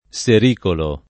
[ S er & kolo ]